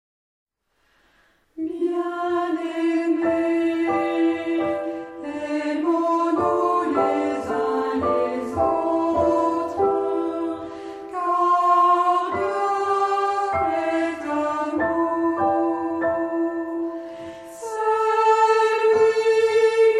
Género/Estilo/Forma: Sagrado ; Antífona
Carácter de la pieza : entrañablemente ; calma
Tipo de formación coral: unisson
Instrumentos: Organo (1)
Tonalidad : mi frigio